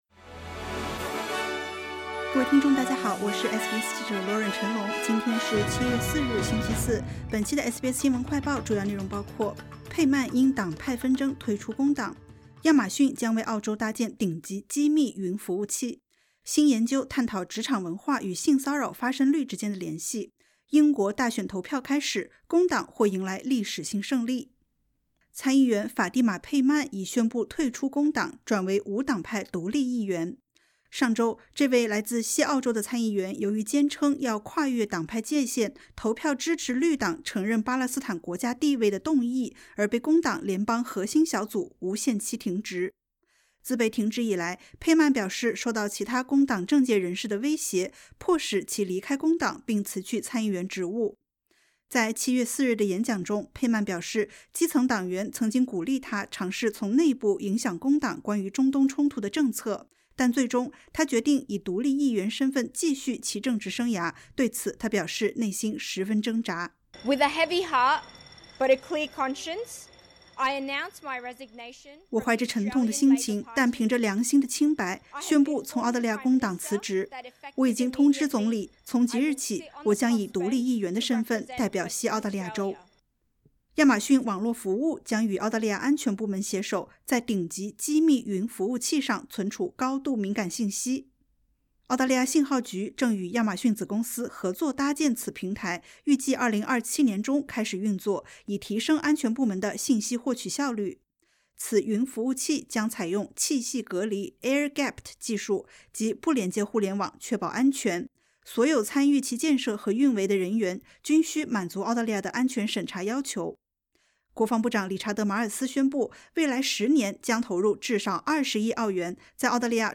【SBS新闻快报】佩曼因党派纷争 宣布转为独立议员